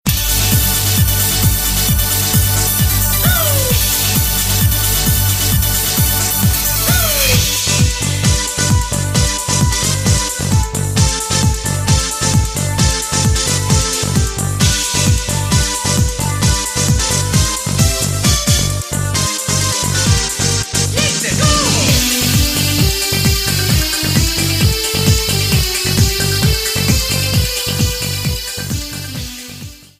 Trimmed and fadeout